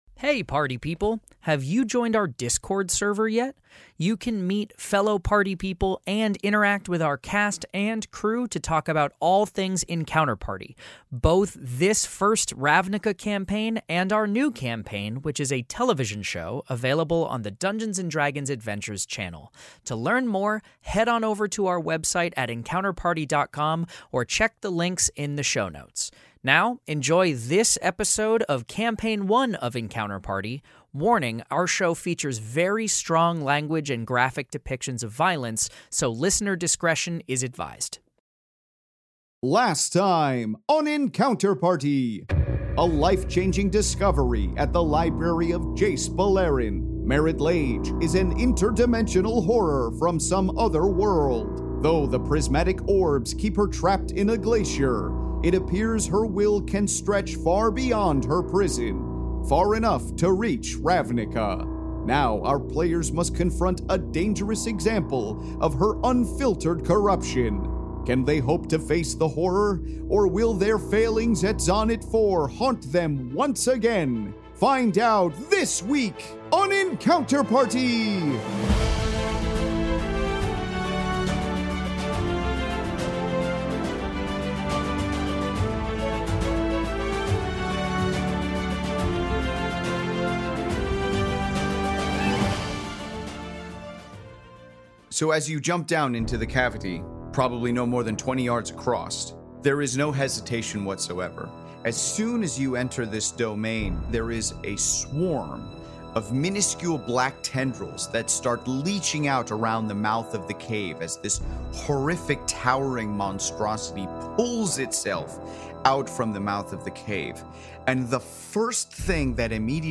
Step inside the Magic: the Gathering world of Ravnica in this Fantasy Mystery Audio Adventure governed by the rules of Dungeons & Dragons